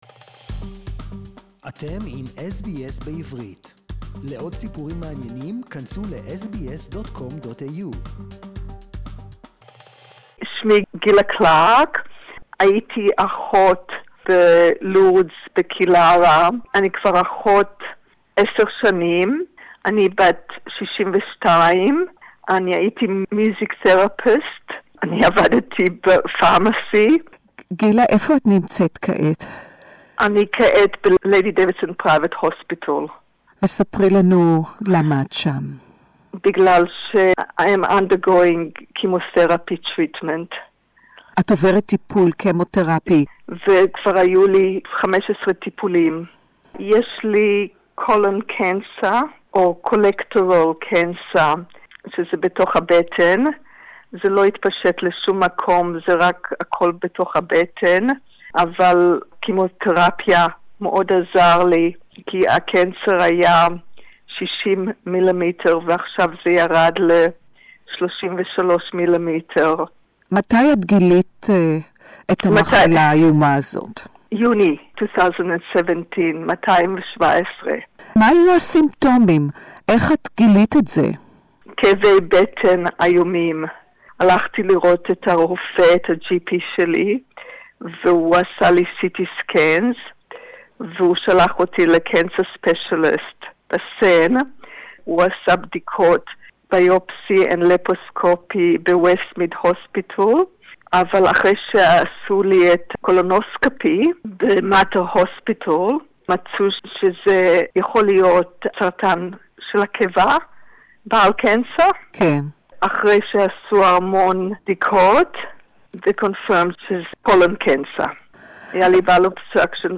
Interview in Hebrew.